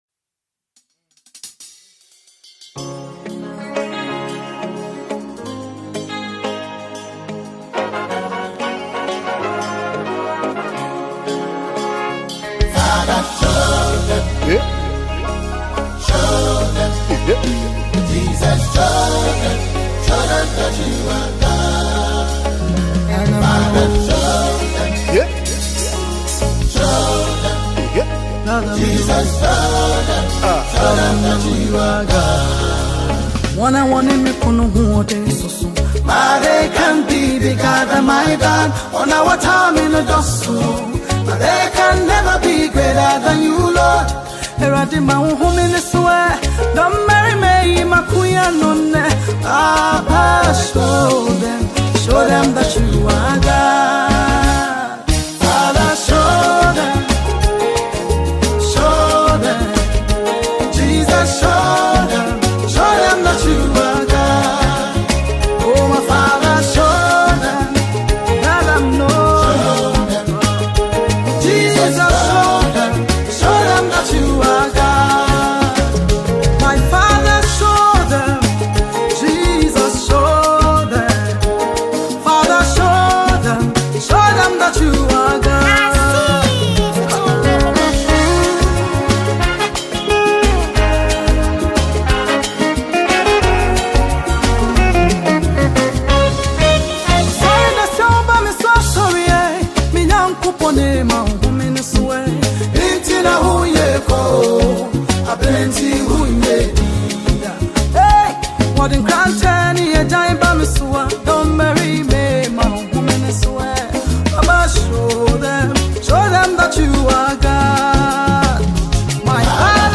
Ghanaian gospel musician